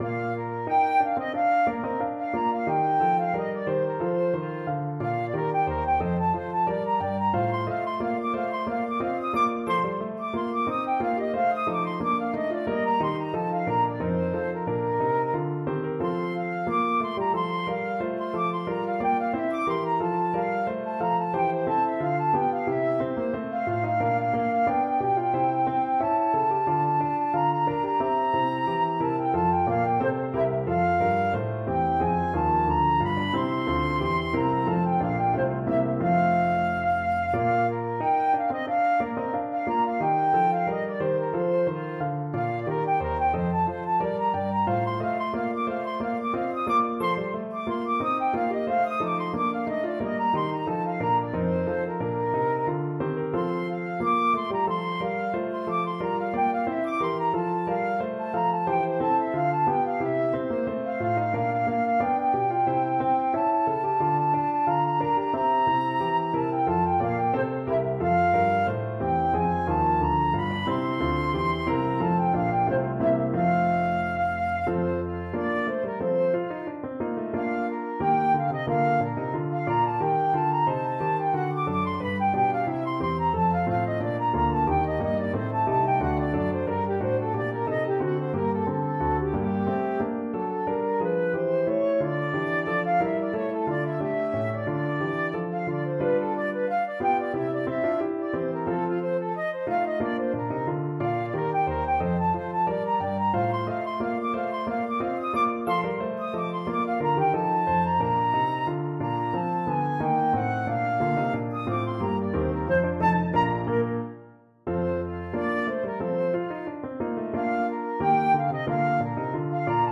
Flute
4/4 (View more 4/4 Music)
= 90 Allegro (View more music marked Allegro)
Arrangement for Flute and Piano
Bb major (Sounding Pitch) (View more Bb major Music for Flute )
Classical (View more Classical Flute Music)